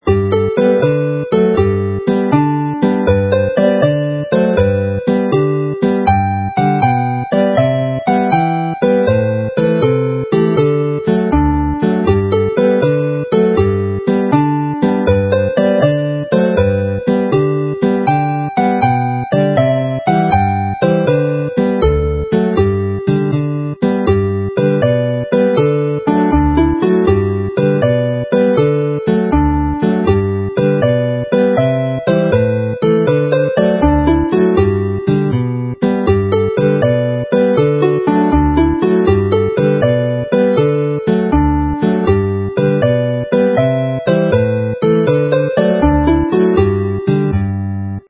jolly jigs
a lively formal Welsh dance